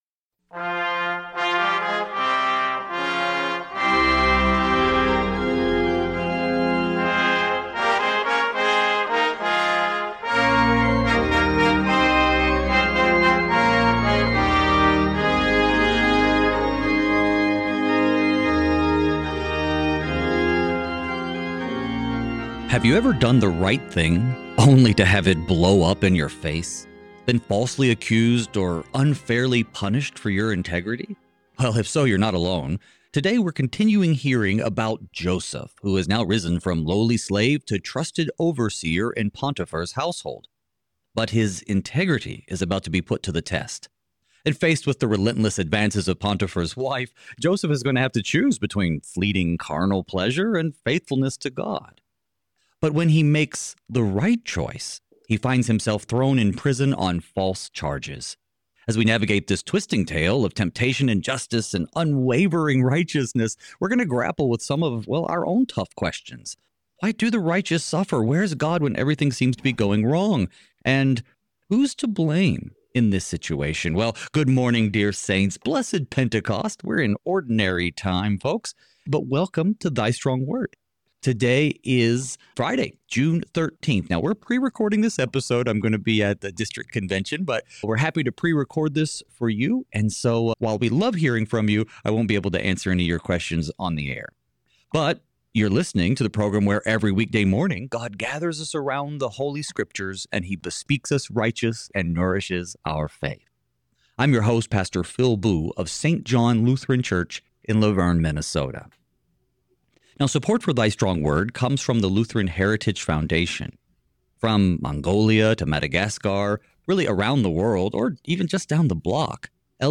Thy Strong Word reveals the light of our salvation in Christ through study of God’s Word, breaking our darkness with His redeeming light. Each weekday, two pastors fix our eyes on Jesus by considering Holy Scripture, verse by verse, in order to be strengthened in the Word and be equipped to faithfully serve in our daily vocations.